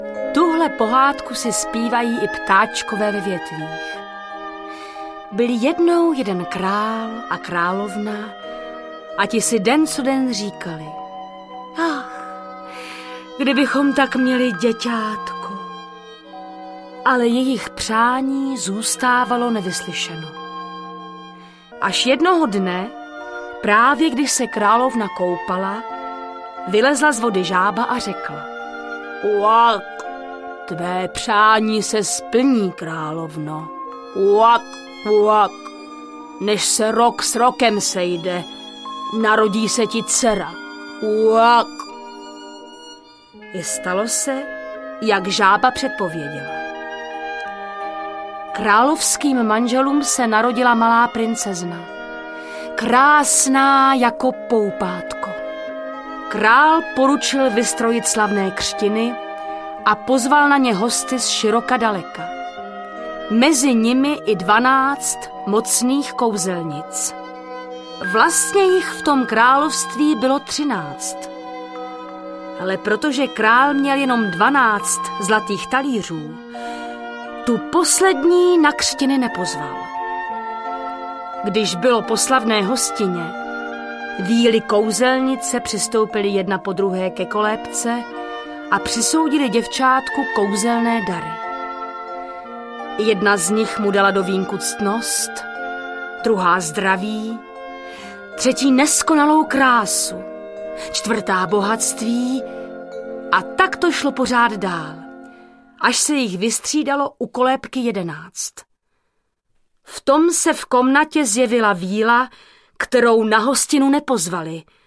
Velká kolekce českých pohádek audiokniha
Ukázka z knihy